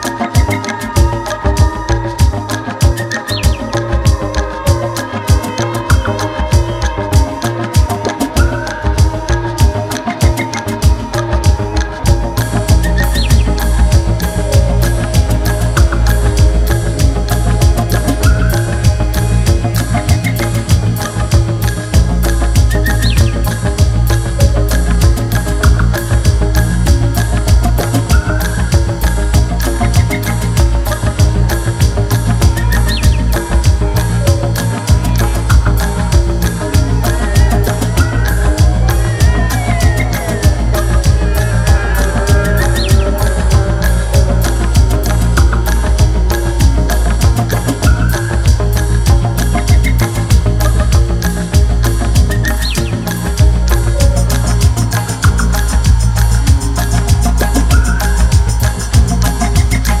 バレアリックやコズミック系DJも要チェックな個性的なサウンドがギッシリ！